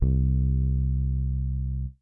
BAL Bass C1.wav